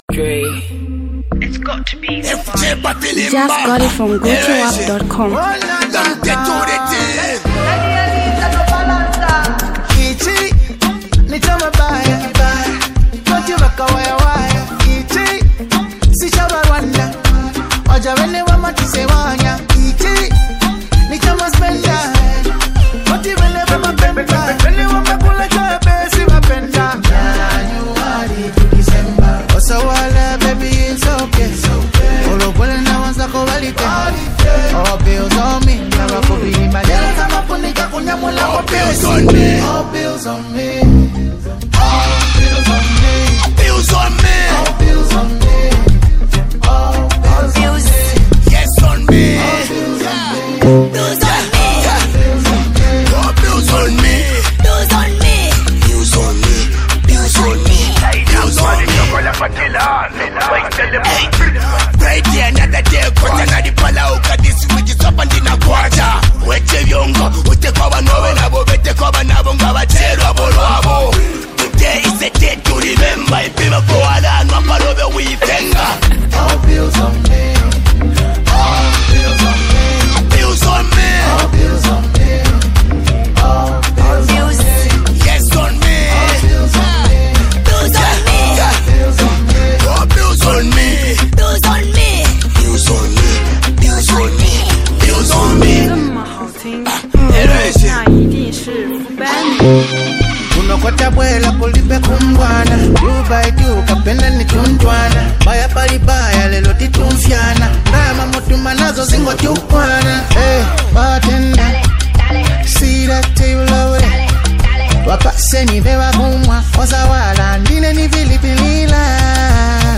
Zambian Mp3 Music
street anthem